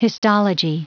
Prononciation du mot histology en anglais (fichier audio)
Prononciation du mot : histology